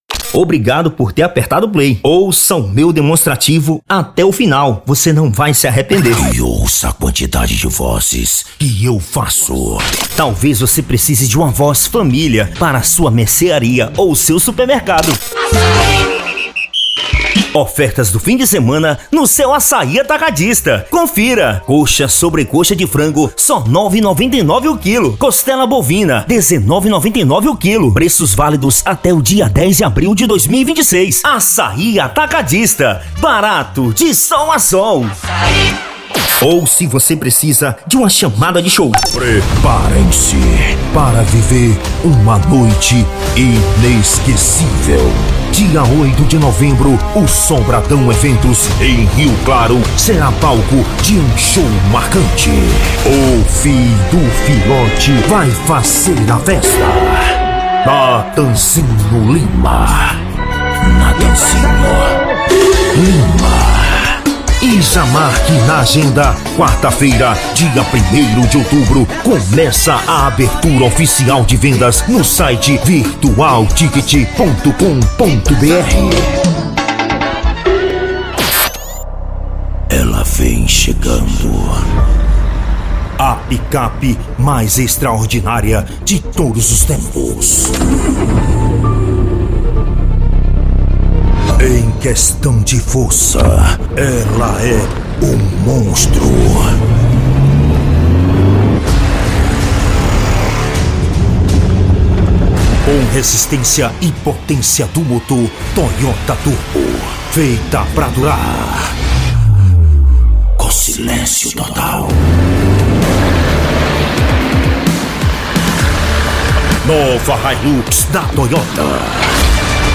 PALHAÇO: